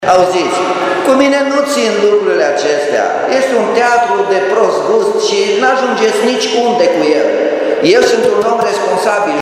Aproximativ 30 de persoane au participat la întâlnirea organizată de primar la solicitarea cetățenilor. În cele două ore de discuții, au fost și momente tensionate, în care Nicolae Robu a reacționat la mici ironii ale unora dintre participanți: